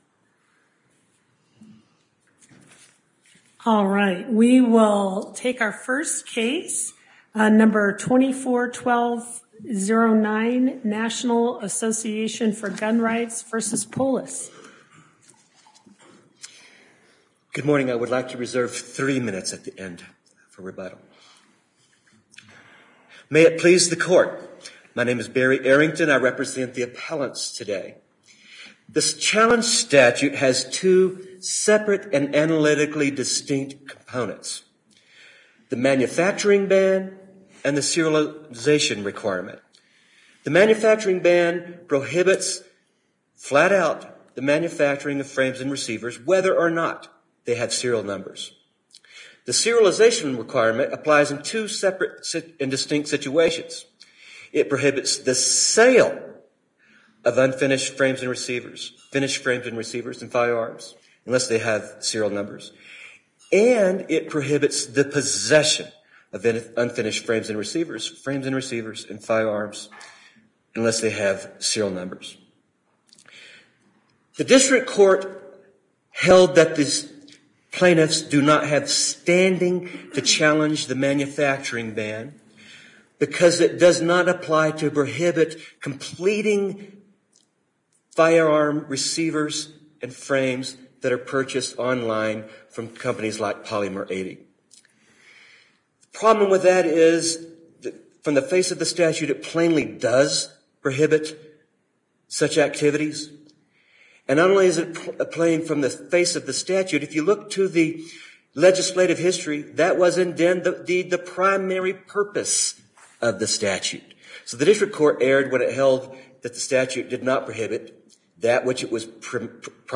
NAGR-CO-Ghost-Gun-Oral-Arguments.mp3